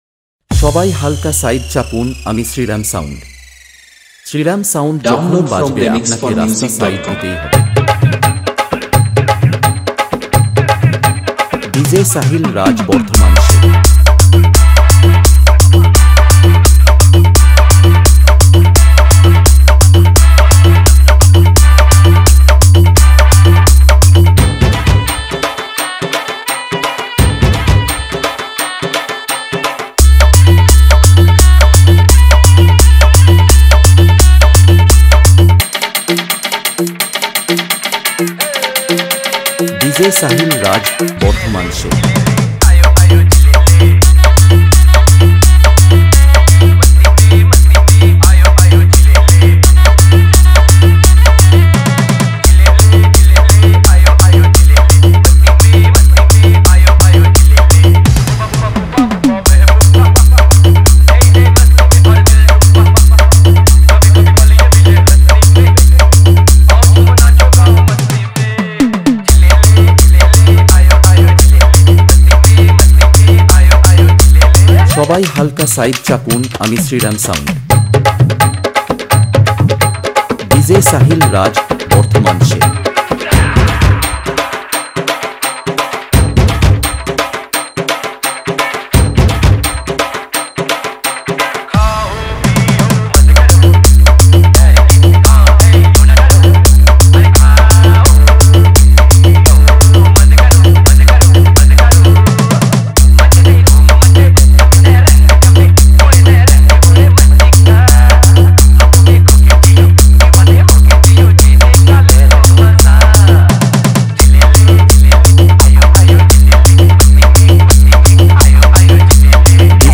Category : Sound Check Remix Song